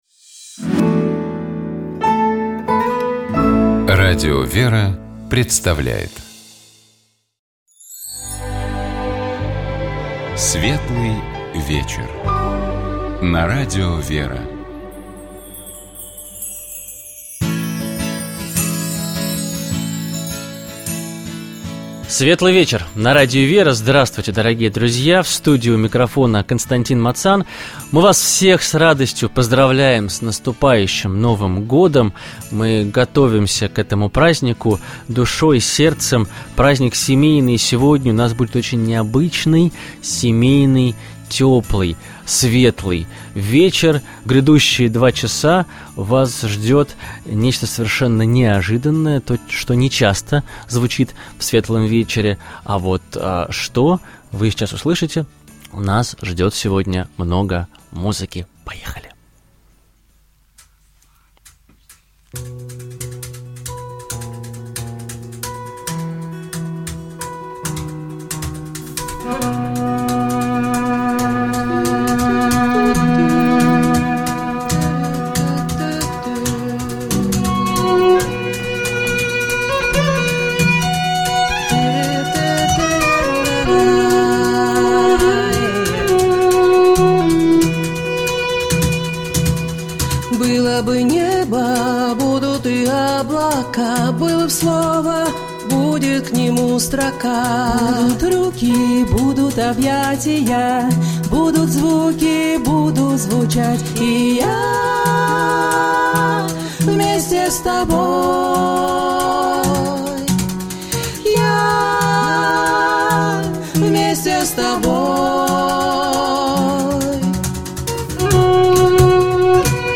Беседа из цикла про образование, который Радио ВЕРА организует совместно с образовательным проектом «Клевер Лаборатория», которая объединяет учителей, руководителей школ и детских садов, родителей и всех тех, кто работает с детьми и занимается их духовно-нравственным развитием.